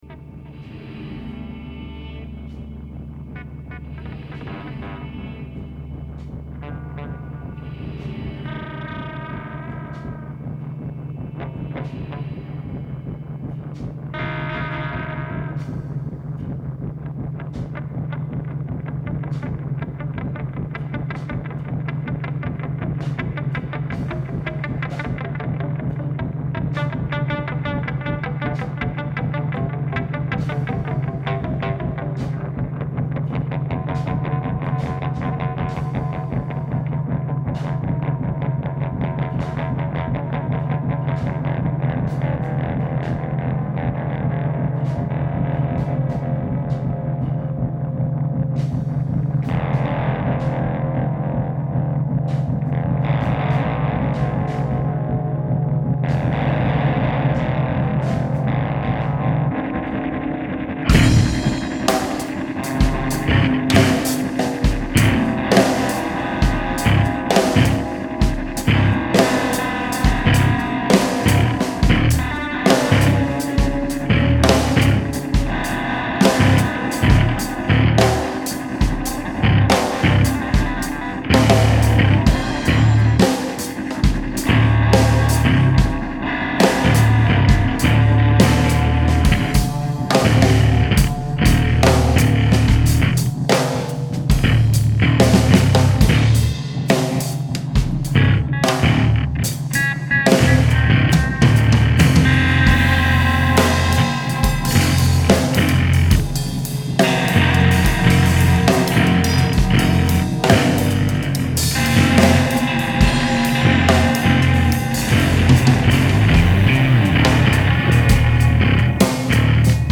impro- jazz / avant-rock
guitare, basse, voix
batterie